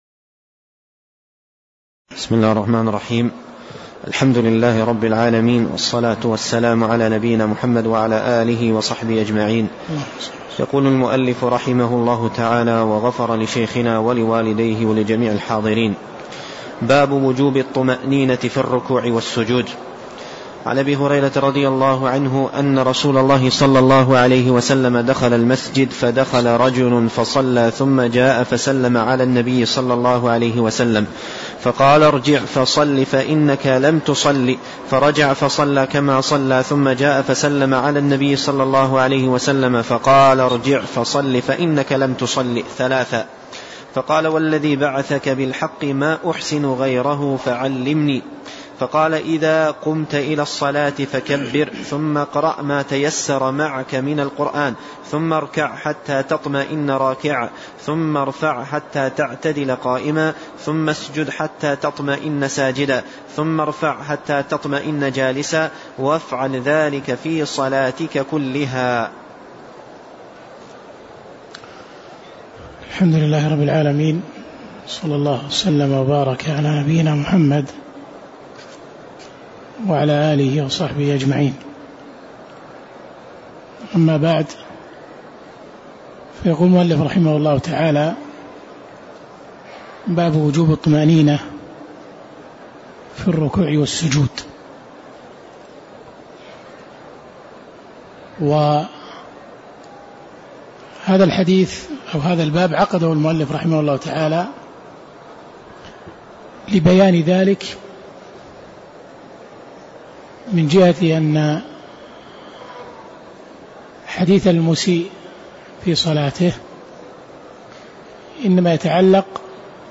تاريخ النشر ٤ صفر ١٤٣٧ هـ المكان: المسجد النبوي الشيخ